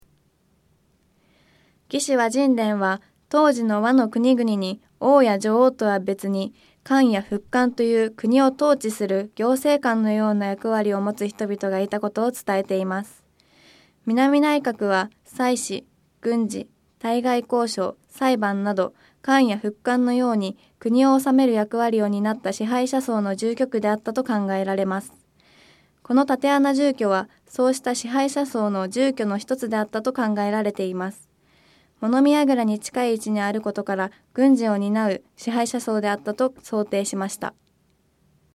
音声ガイド 前のページ 次のページ ケータイガイドトップへ (C)YOSHINOGARI HISTORICAL PARK